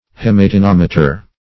Search Result for " hematinometer" : The Collaborative International Dictionary of English v.0.48: Hematinometer \Hem`a*ti*nom"e*ter\, n. [Hematin + -meter.]
hematinometer.mp3